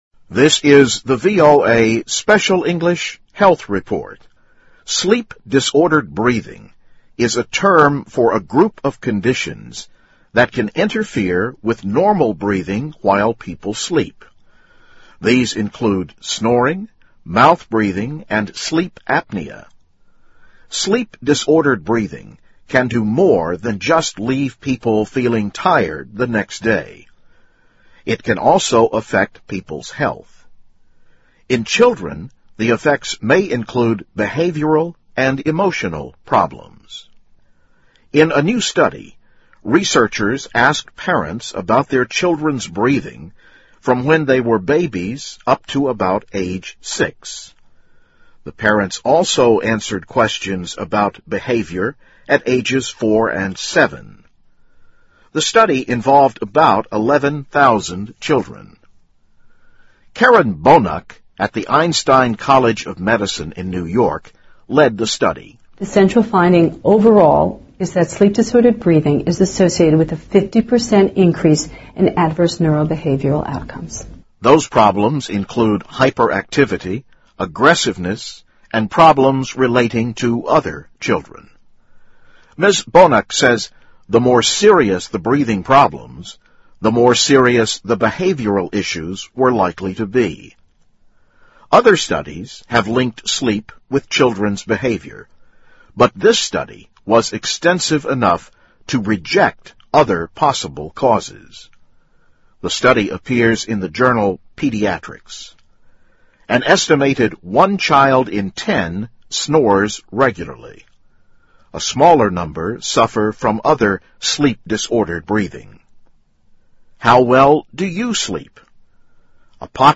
VOA慢速英语2012--Health Report - New Findings on Sleep in Children, Older Adults 听力文件下载—在线英语听力室